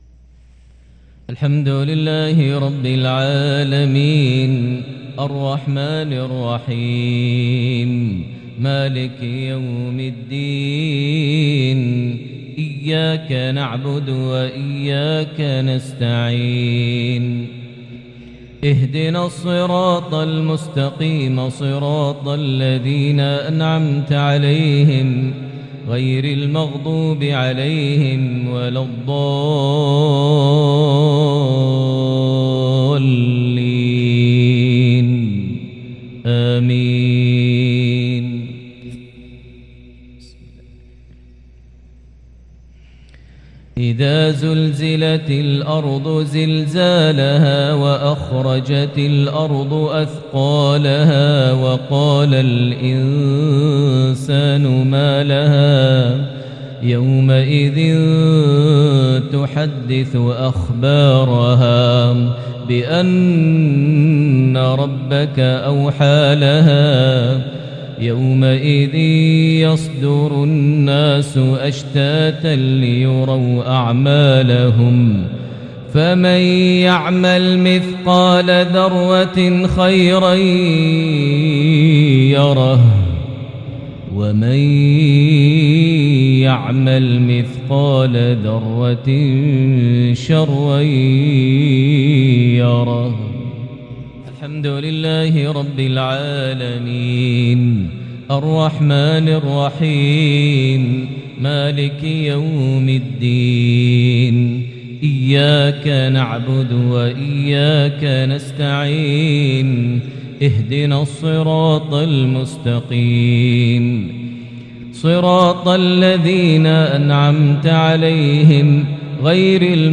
صلاة المغرب من سورتي الزلزلة + القارعة | 2 شعبان 1443هـ| maghrib 5-3-2022 prayer from Surah Al-Zalzala + Surah Al-Qaria > 1443 🕋 > الفروض - تلاوات الحرمين